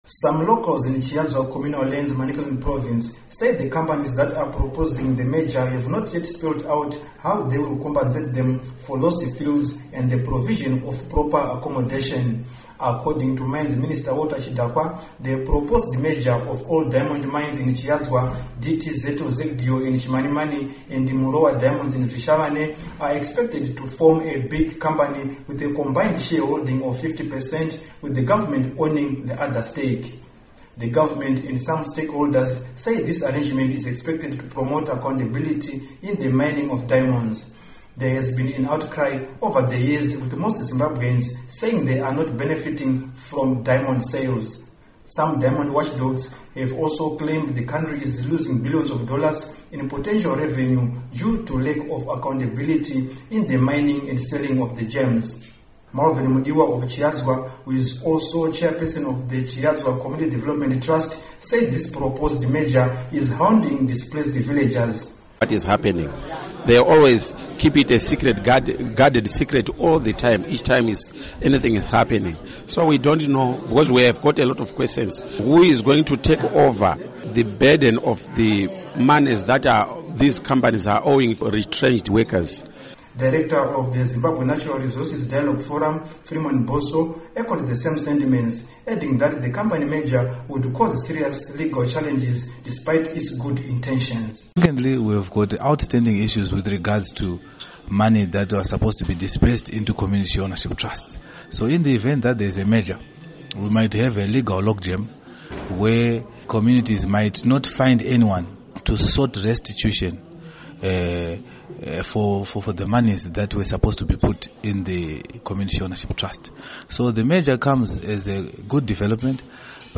Report on Chiadzwa Villagers